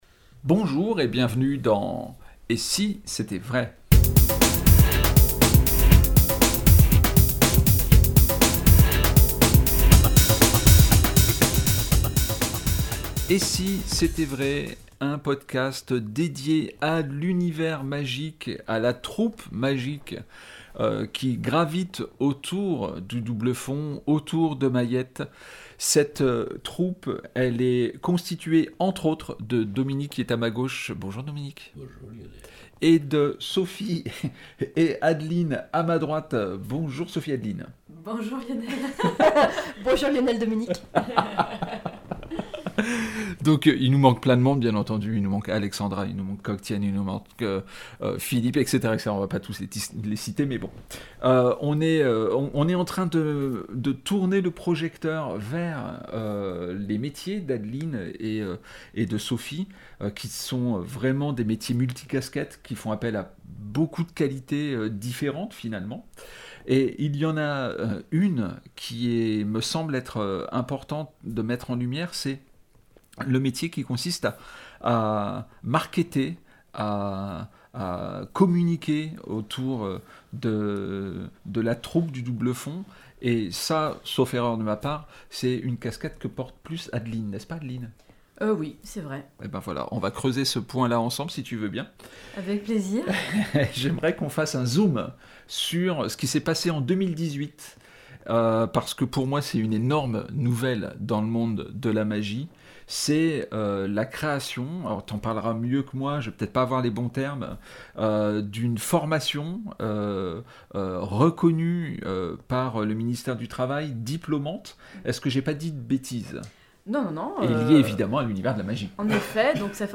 Podcast "Et si c'était vrai ?" émission n°88 - Entretiens